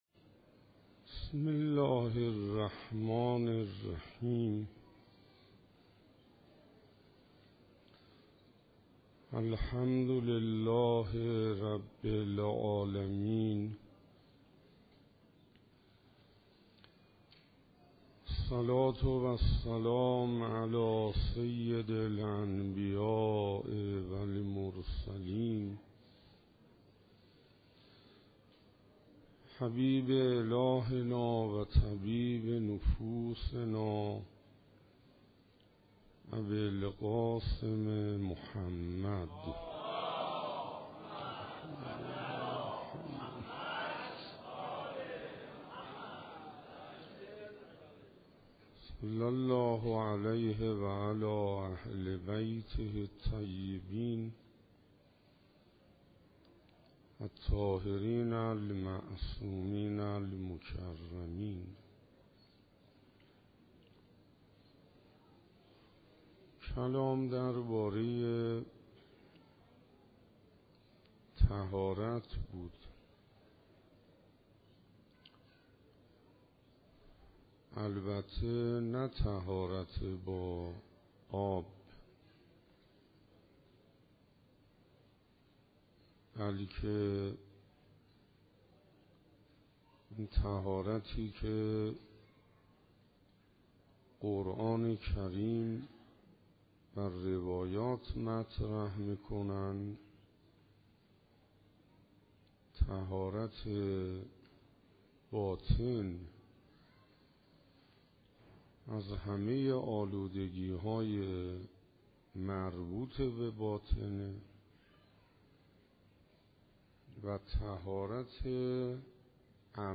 مسجد شهید بهشتی_ جمادی الاول 95 سخنرانی دوم-طهارت باطنی